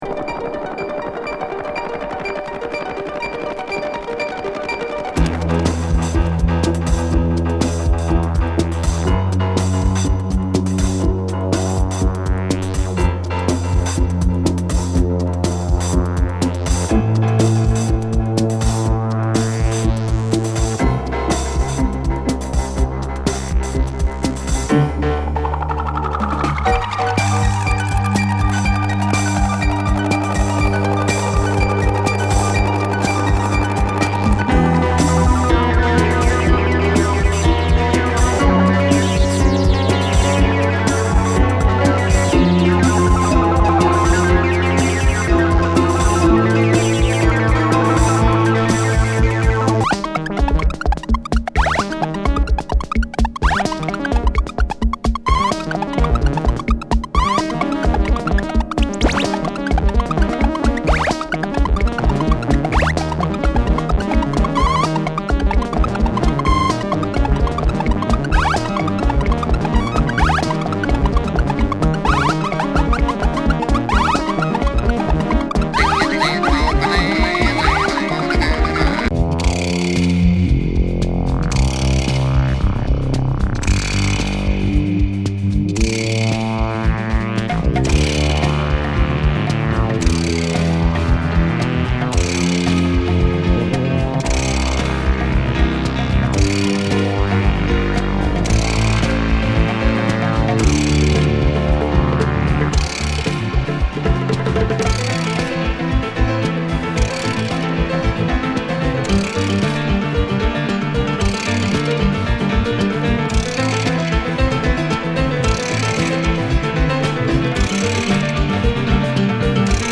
エキセントリック・ヘビー・グルーブ
キッチュ電子
エキゾ・ジャズロック